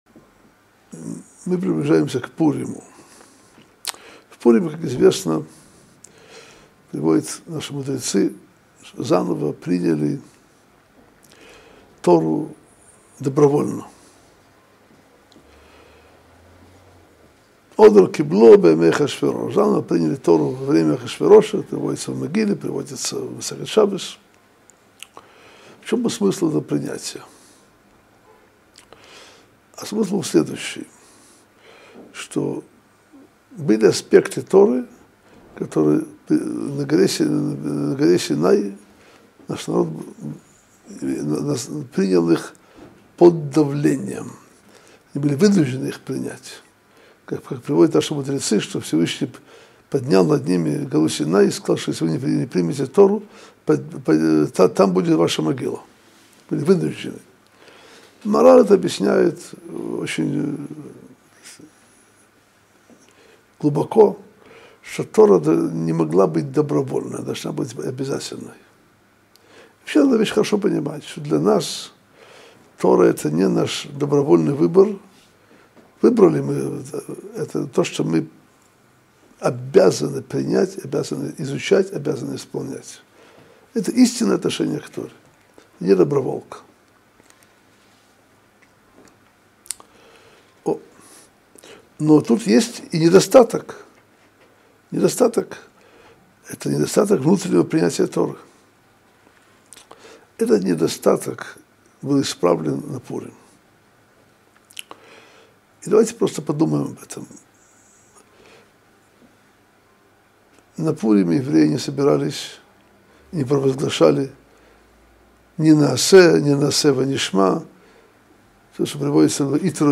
Содержание урока: